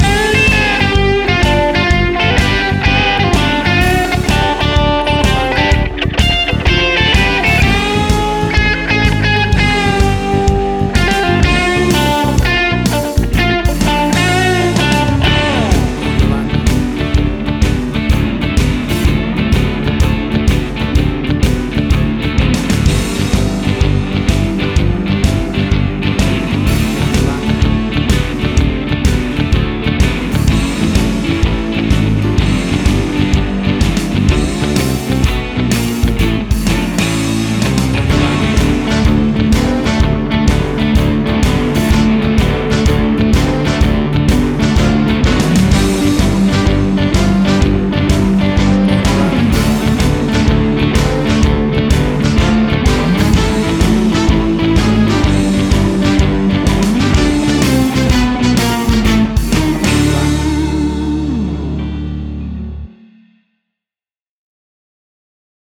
classic piece of vintage raw blues music
WAV Sample Rate: 16-Bit stereo, 44.1 kHz
Tempo (BPM): 126